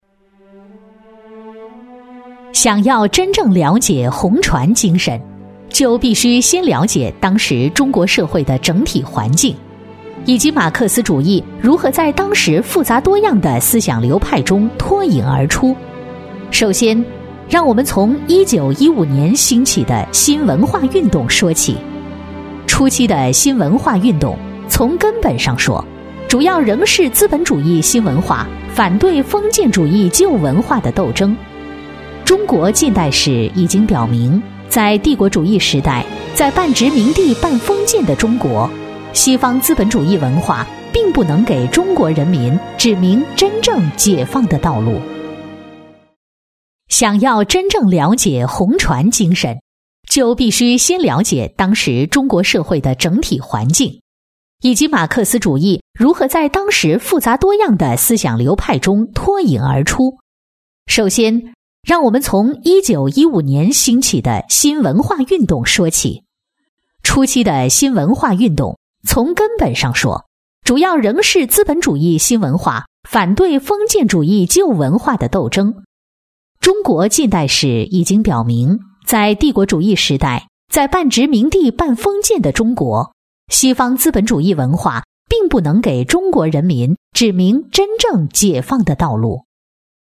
• 女S114 国语 女声 宣传片 马克思主义进入中国 大气 沉稳播音 亲切甜美